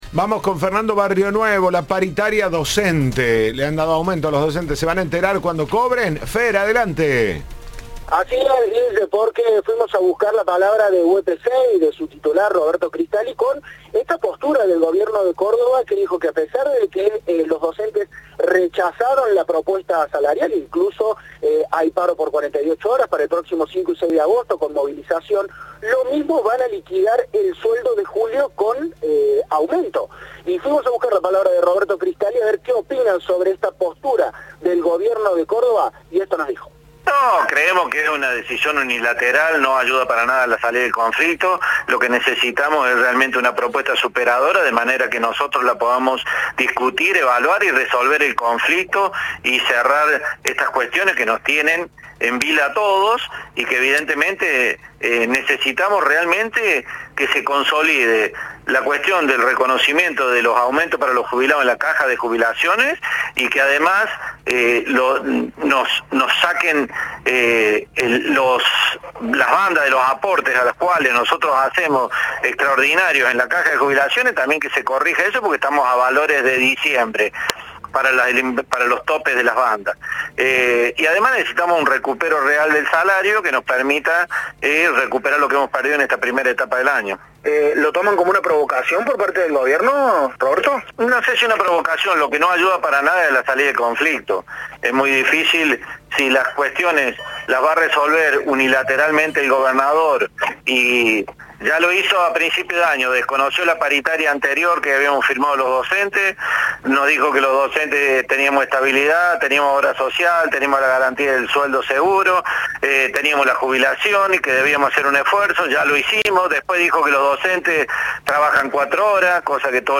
En diálogo con Cadena 3